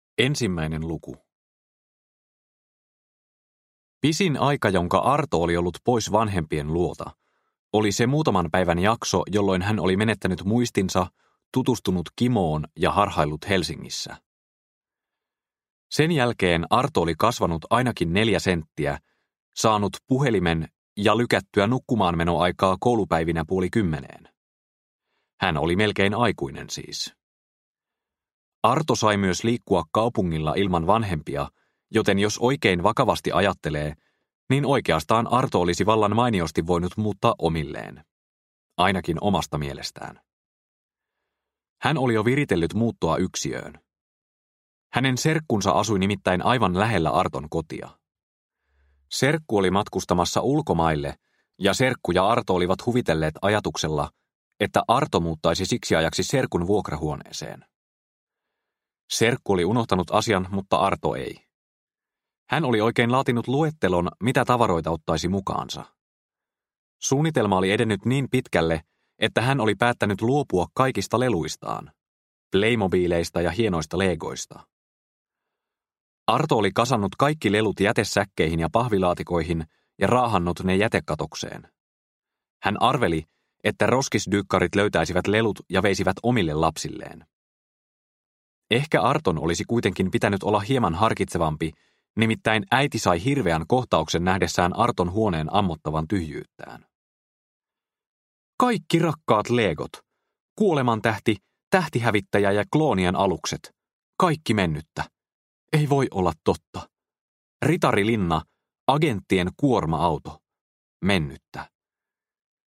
Kesä autokorjaamon yläkerrassa – Ljudbok – Laddas ner